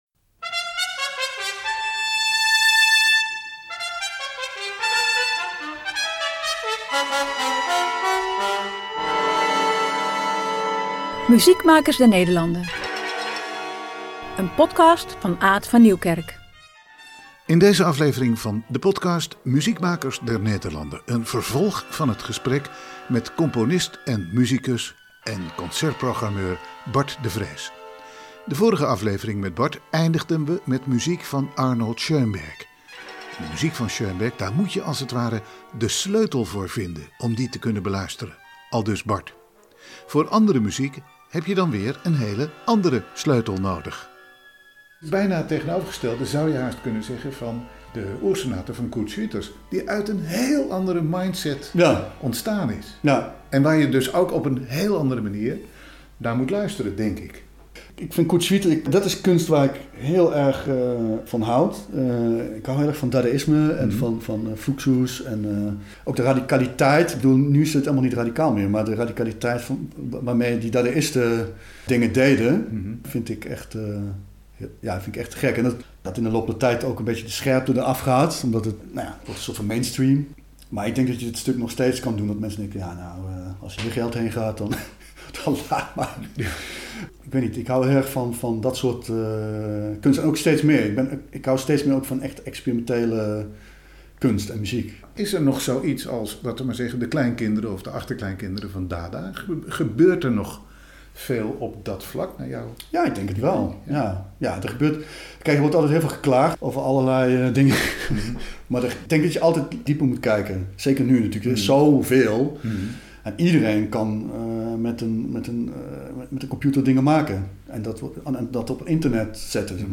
Portretten van makers, componisten en musici in Nederland, aan de hand van hun ‘desert island discs’ – de muziekstukken die ze graag aan de hele wereld willen laten horen, en die een belangrijke rol in hun werk en leven spelen.